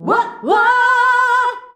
UAH-UAAH B.wav